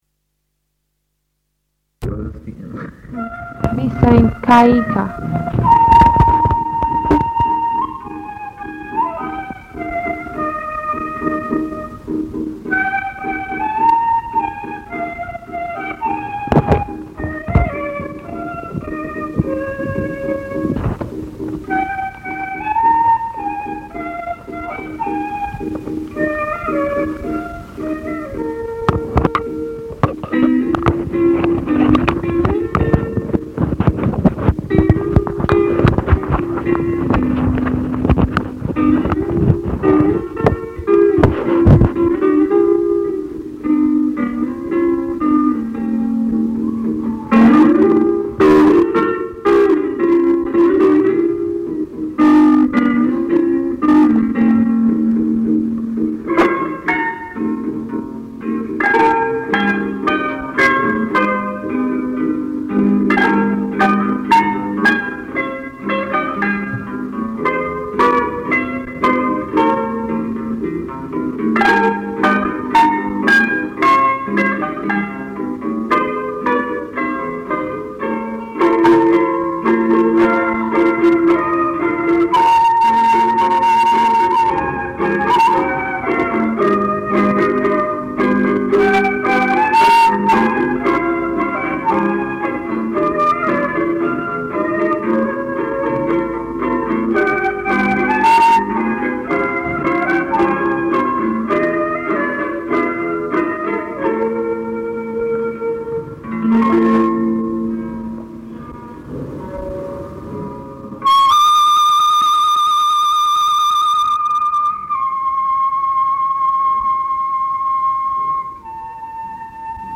Andean music including flute and drum
From the sound collections of the Pitt Rivers Museum, University of Oxford, being one of a number of miscellaneous or individual ethnographic field recordings (rediscovered during a recent research project).